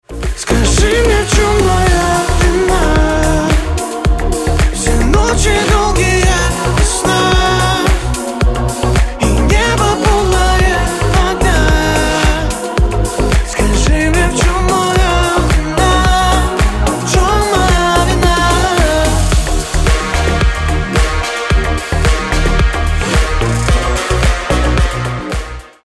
• Качество: 320, Stereo
поп
мужской вокал
грустные